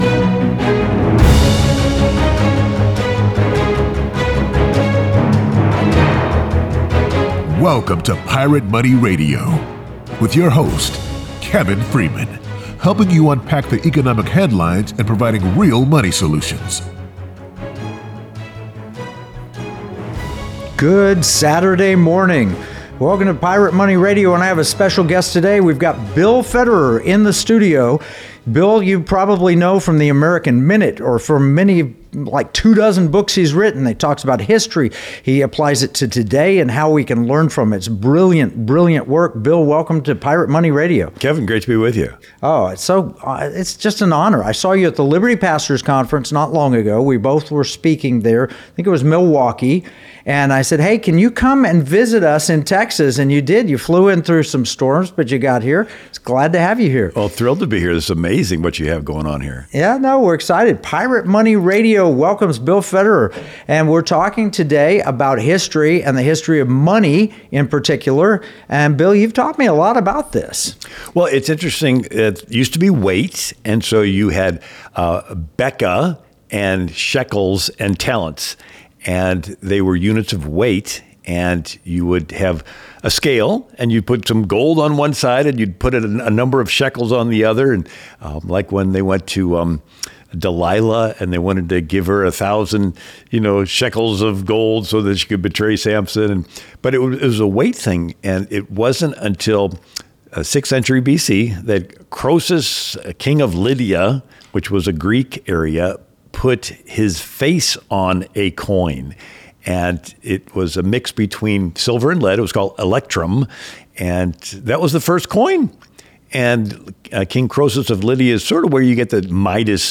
Welcome to Pirate Money Radio